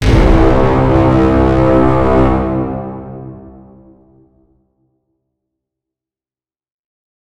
Angry Boat - Epic Movie Horn